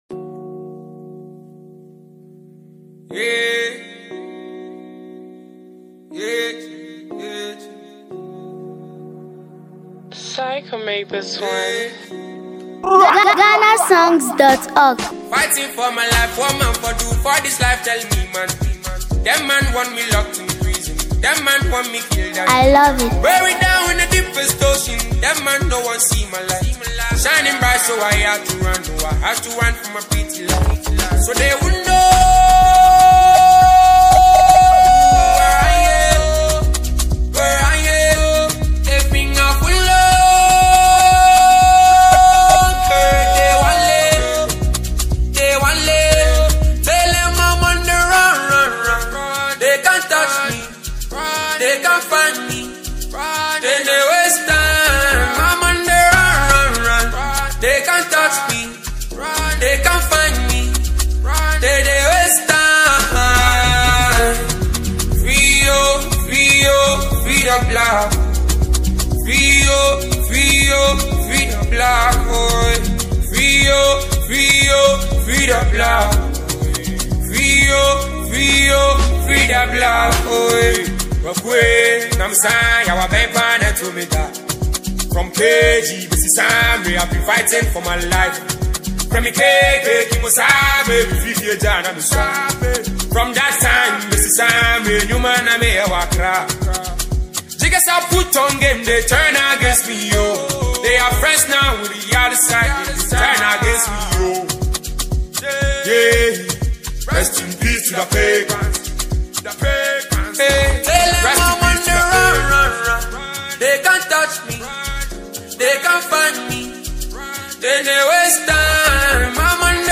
an emotional and reflective song
With heartfelt lyrics and a soulful delivery